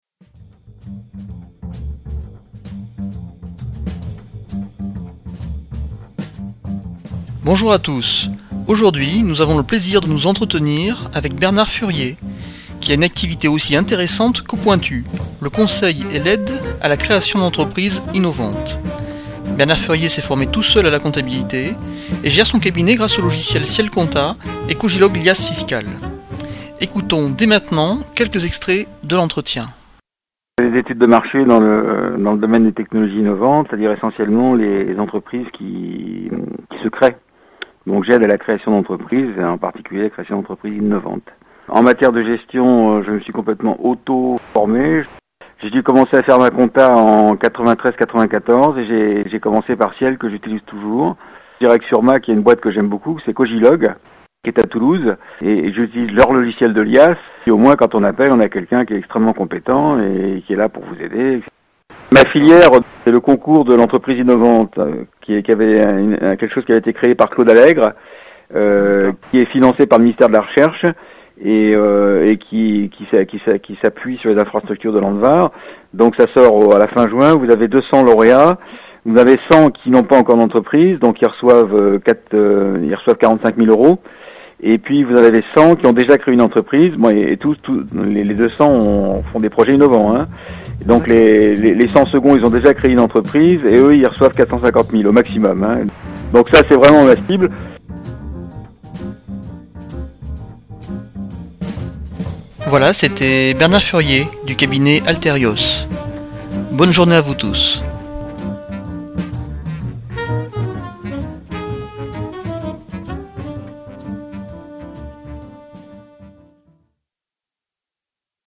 PodCast Interview Logiciel Comptabilité Ciel Compta